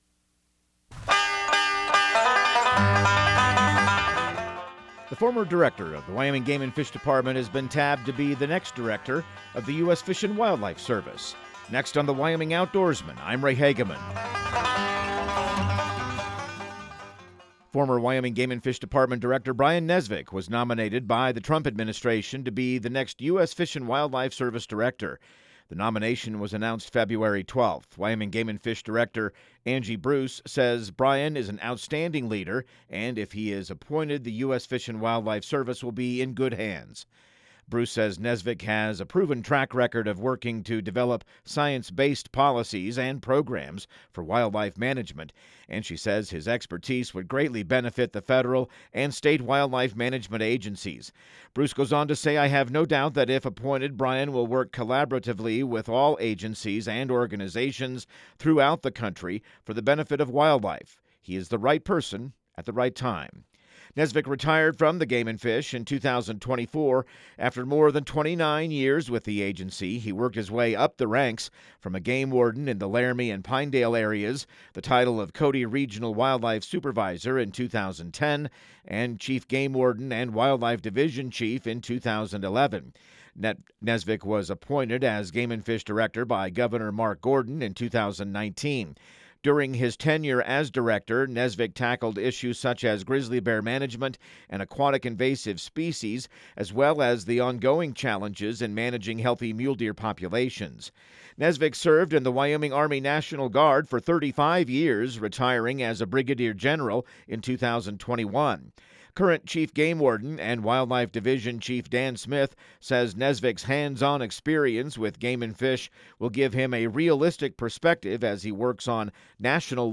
Radio news | Week of February 24